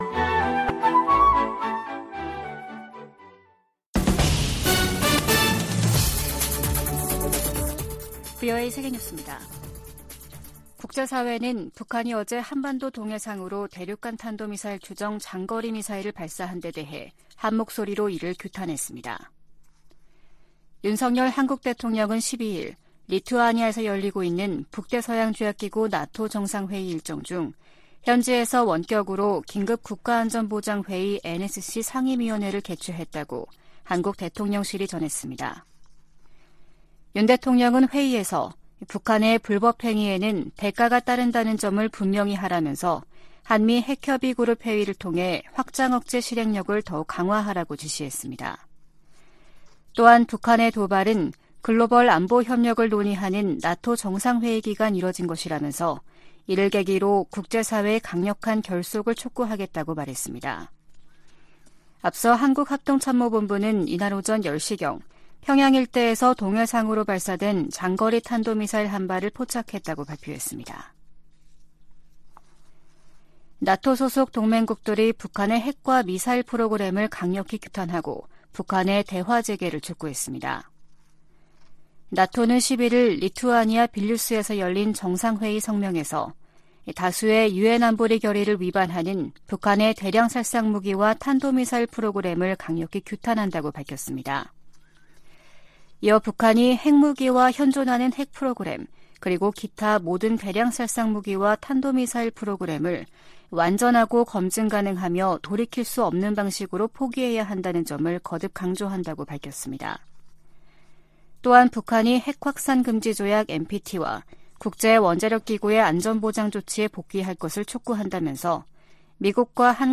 VOA 한국어 아침 뉴스 프로그램 '워싱턴 뉴스 광장' 2023년 7월 13일 방송입니다. 한국 정부가 북한 정권의 대륙간탄도미사일(ICBM) 발사를 규탄하며 불법 행위에는 대가가 따를 것이라고 경고했습니다. 미국과 한국, 일본의 북 핵 수석대표들도 북한의 ICBM 발사는 유엔 안보리 결의를 위반하는 심각한 도발로, 어떤 이유로도 정당화할 수 없다고 비판했습니다. 미 국무부는 미군 정찰기가 불법 비행했다는 북한 정권의 주장은 근거가 없다고 지적했습니다.